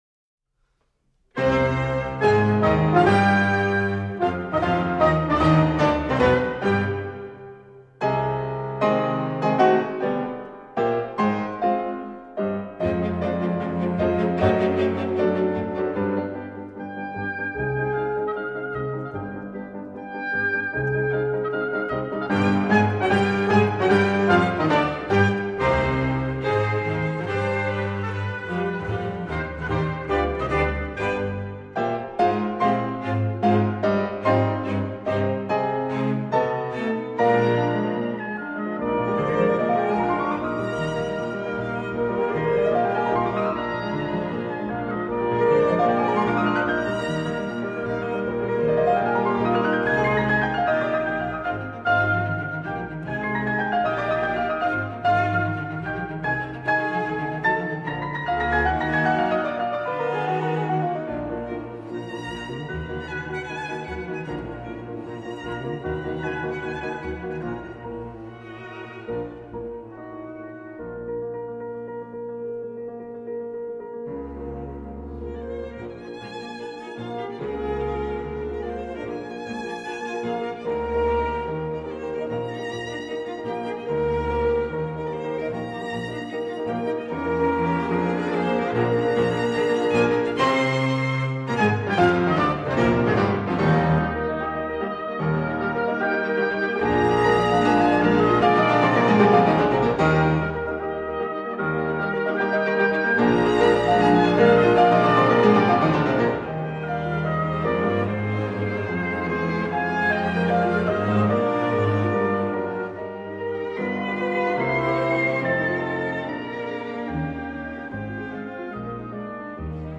موسیقی کلاسیک: پیانو کوارتت شماره یک از آهنگساز آلمانی الکساندر ارنست فسکا
چهار قطعه به هم چسبیده با کیفیت 128 و حجم 30 مگ این قطعه برای سازهای پیانو، ابوا، هورن و ویولن و ویولا و ویولنسل و کنترباس ساخته شده است درباره آهنگساز